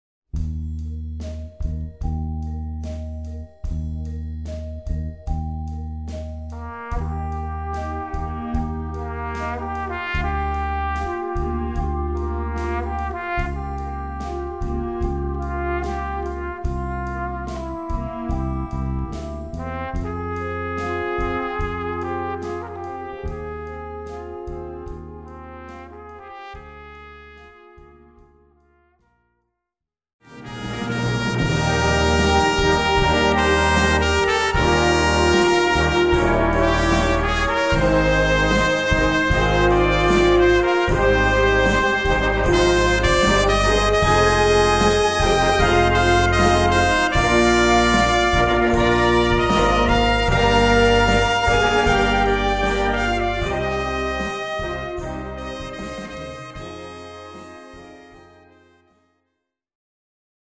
Solo für Flügelhorn, Altsaxophon, Posaune und Trompete.
Solo für diverse Instrumente und Blasorchester
Besetzung: Blasorchester